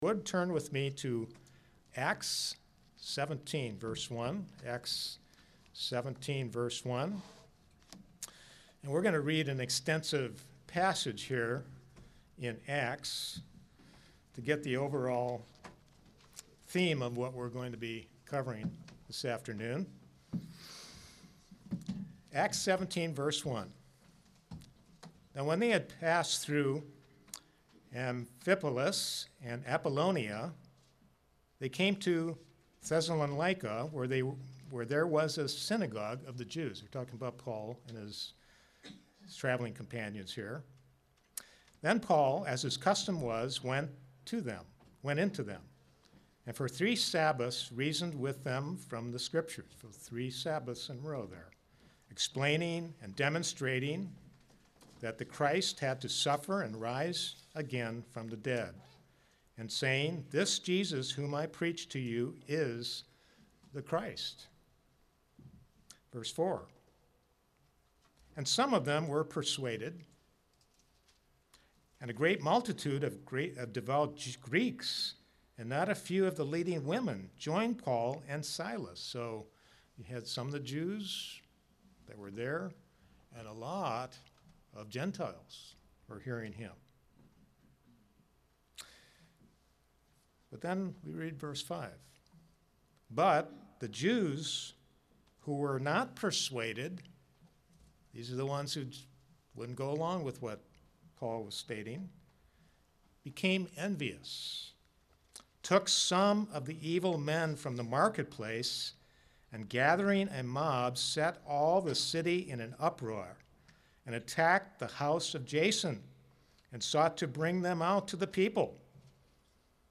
Sermons
Given in Kingsport, TN